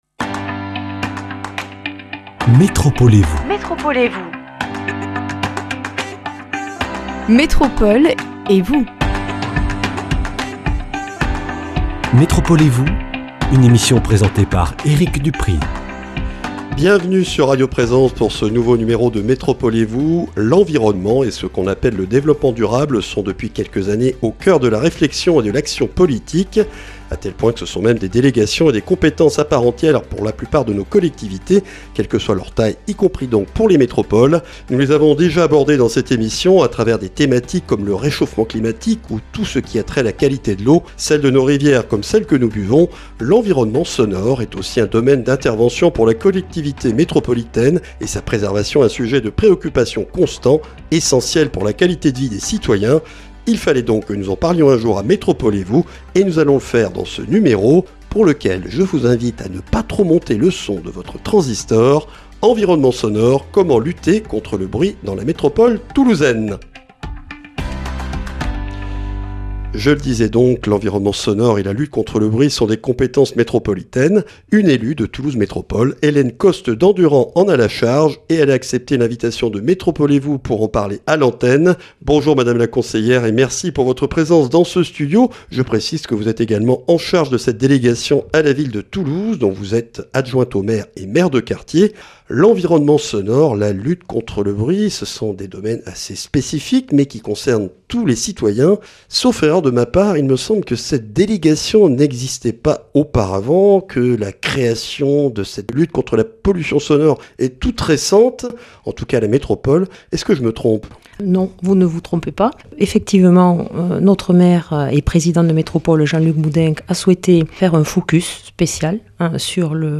La lutte contre les nuisances sonores est un enjeu de santé publique, crucial pour la qualité de vie des citoyens. Un point sur la situation et les actions en cours dans la métropole toulousaine avec Hélène Costes-Dandurand, adjointe au maire de Toulouse et conseillère métropolitaine en charge de la lutte contre la pollution sonore.